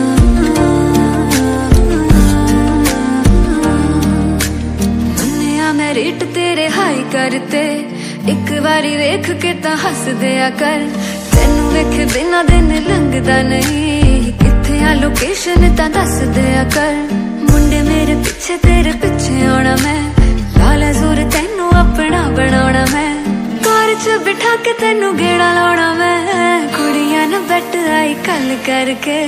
Trendy Punjabi love-chat melody hook ringtone